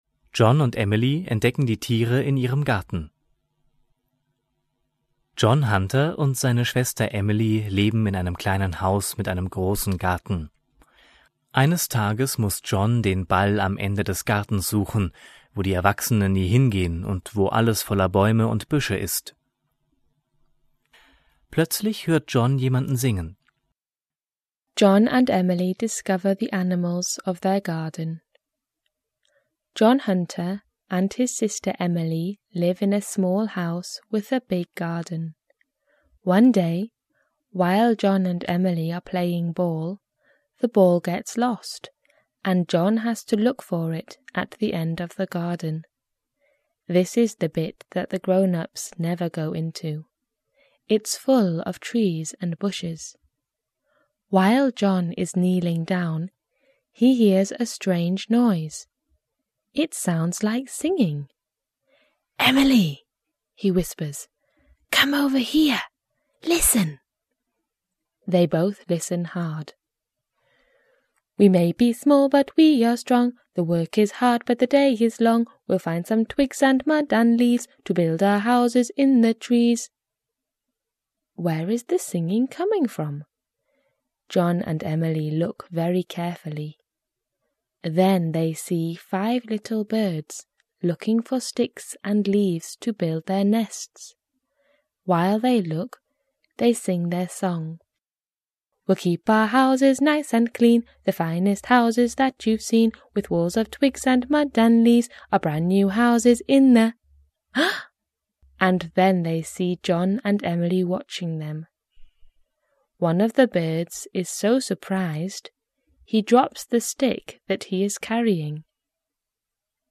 Vor jeder englischen Geschichte ist eine kurze Einführung auf Deutsch gegeben, die den Inhalt der Geschichte kurz zusammenfasst. Alle Geschichten sind von englischen Muttersprachlern gesprochen, um den Kindern ein Gefühl für die Sprachmelodie und Aussprache zu vermitteln.